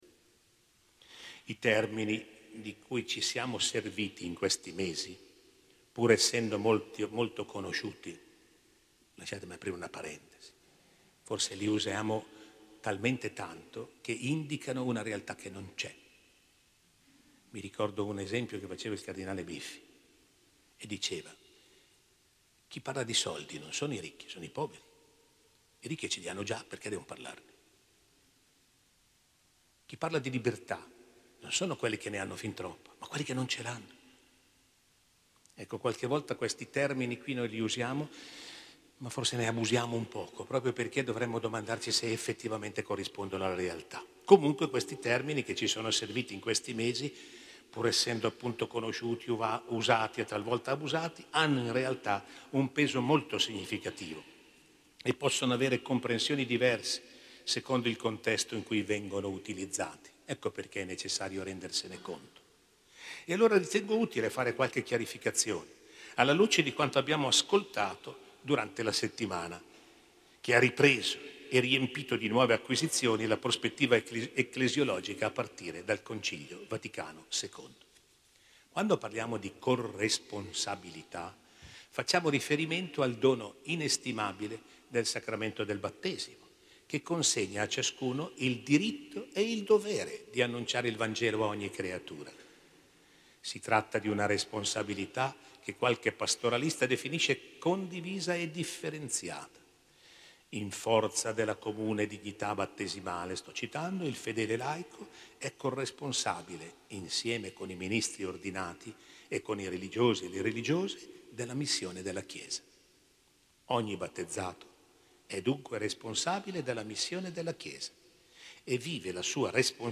Registrazioni e materiale relativo all'incontro conclusivo del 28 Novembre in S. Barbara.